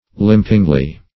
limpingly - definition of limpingly - synonyms, pronunciation, spelling from Free Dictionary Search Result for " limpingly" : The Collaborative International Dictionary of English v.0.48: Limpingly \Limp"ing*ly\ (l[i^]mp"[i^]ng*l[y^]), adv.
limpingly.mp3